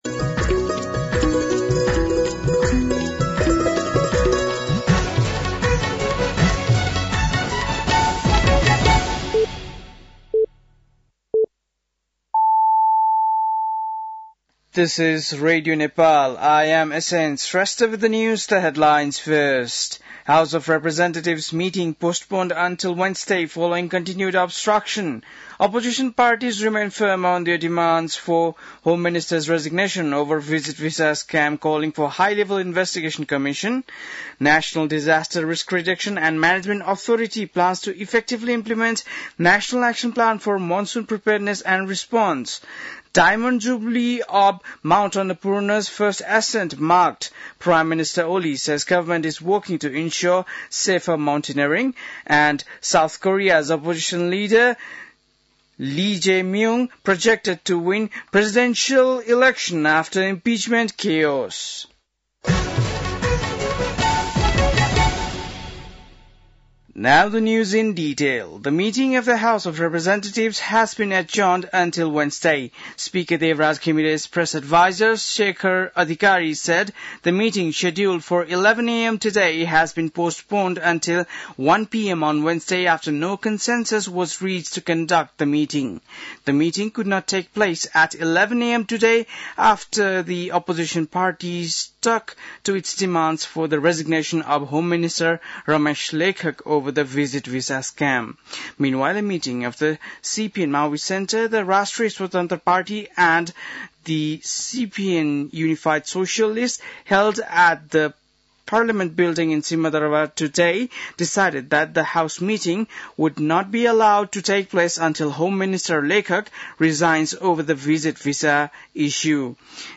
बेलुकी ८ बजेको अङ्ग्रेजी समाचार : २० जेठ , २०८२
8-pm-NEWS-02-20.mp3